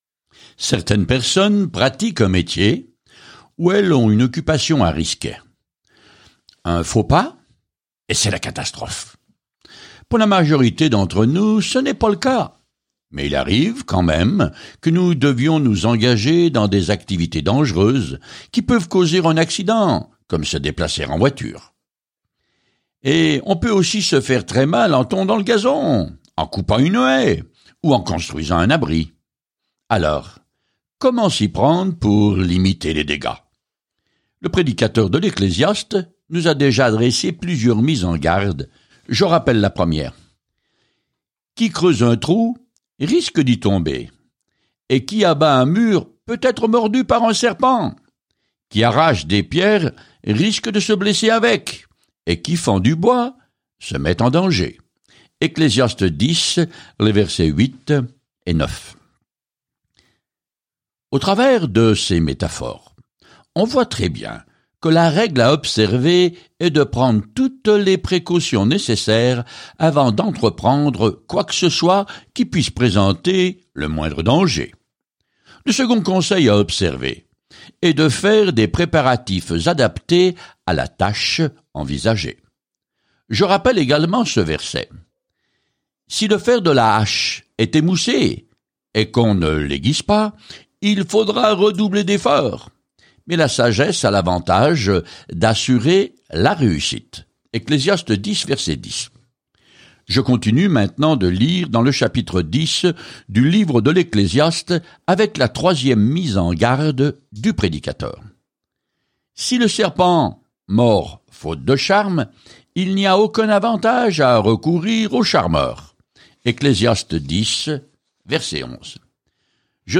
Écritures Ecclésiaste 10:8-20 Ecclésiaste 11 Jour 10 Commencer ce plan Jour 12 À propos de ce plan L’Ecclésiaste est une autobiographie dramatique de la vie de Salomon alors qu’il essayait d’être heureux sans Dieu. En voyageant quotidiennement à travers l’Ecclésiaste, vous écoutez l’étude audio et lisez des versets sélectionnés de la parole de Dieu.